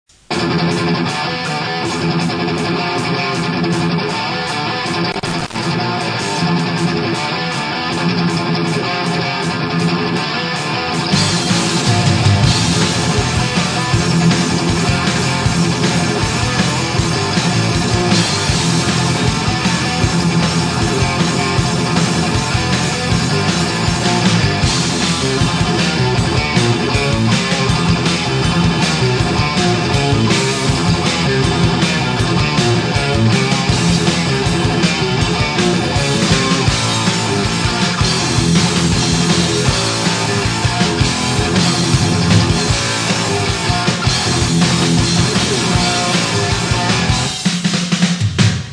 Список файлов рубрики Примеры Trаsh'а
Данный пример исполнен в стиле trаsh+sрееd.